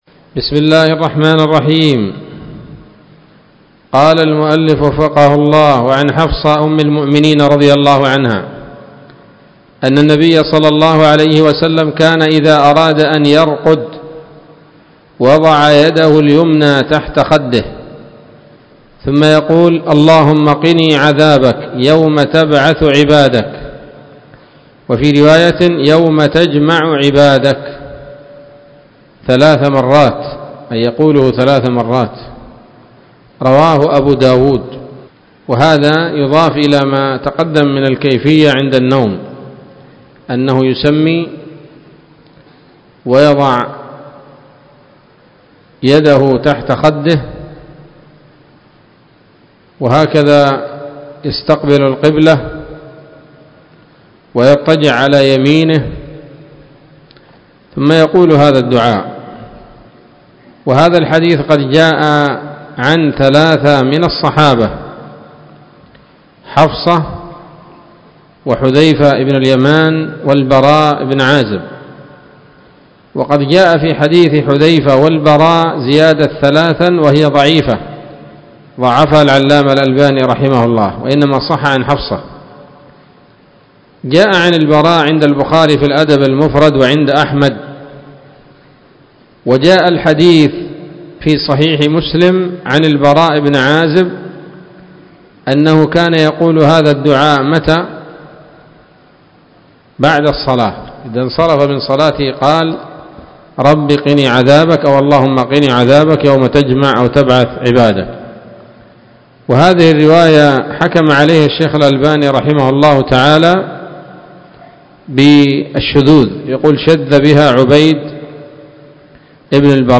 الدرس الخامس عشر من رياض الأبرار من صحيح الأذكار